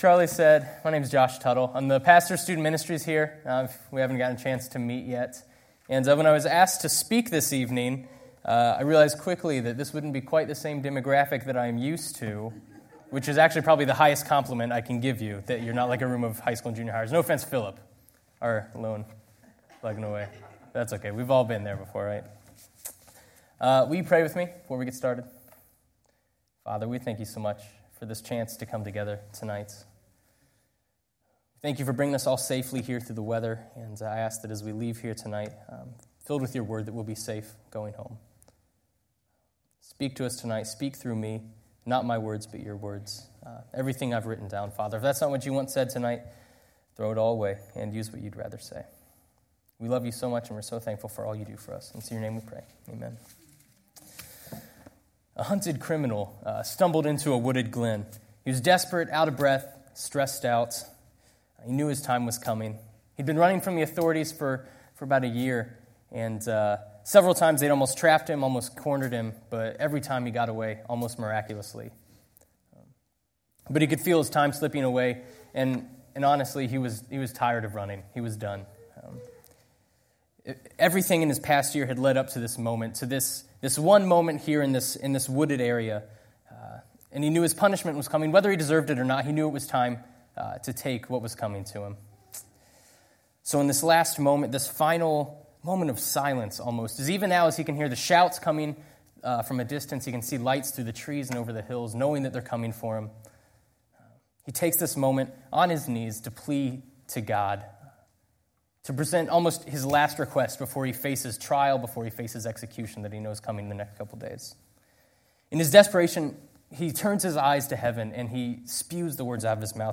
Sunday Evening Message